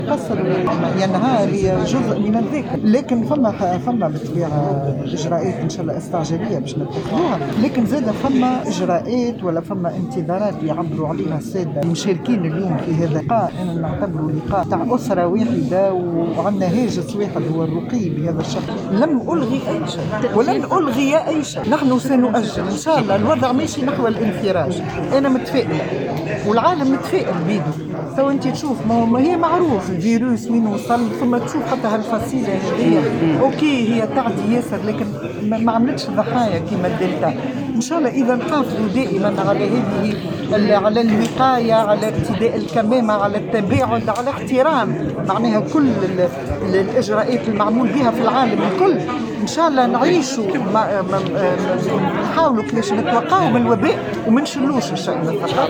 لدى اشرافها على المجلس الجهوي الثقافي،أكدت وزيرة الشؤون الثقافية حياة قطاط القرمازي لمراسلنا بتطاوين، أنه سيقع اتخاذ كافة الإجراءات اللازمة لمعالجة نقائص المشهد الثقافي في الجهة، بعد القيام بدراسة كاملة “.